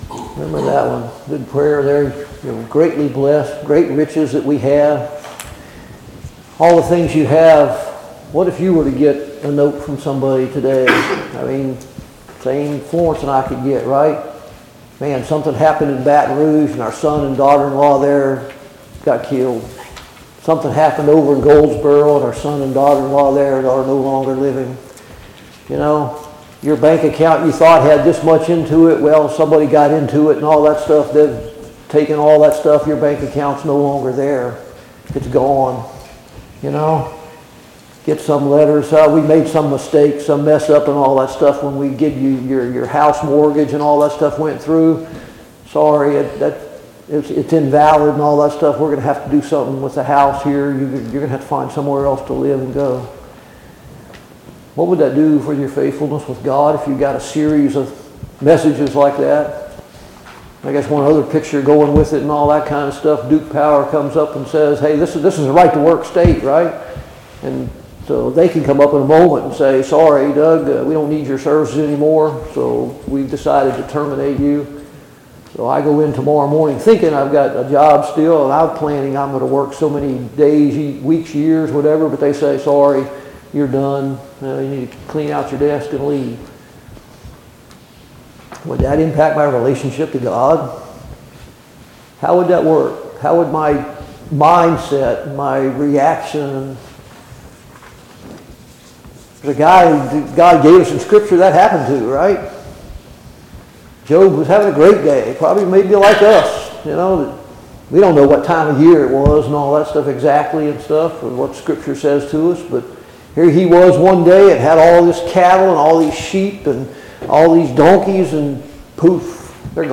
Study on the Minor Prophets Passage: Hosea 6:1-11, Hosea 7:1-16 Service Type: Sunday Morning Bible Class « 5.